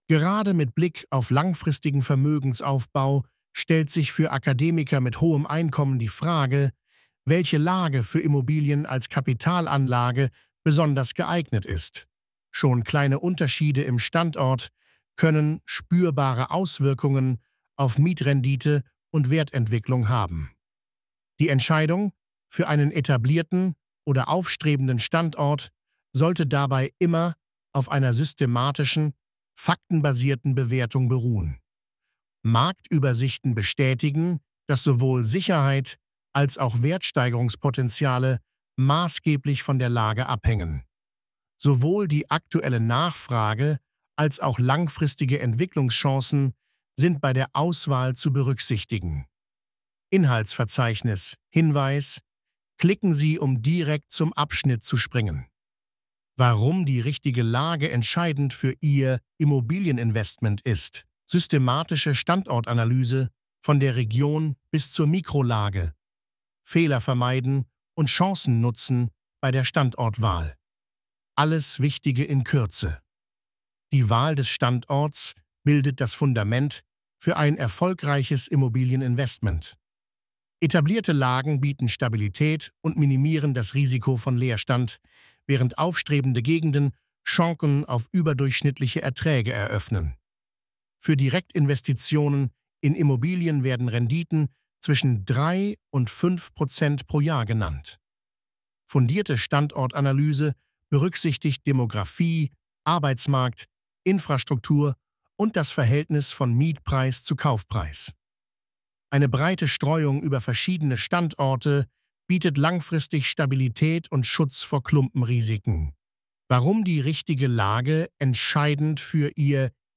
Lassen Sie sich den Artikel von mir vorlesen.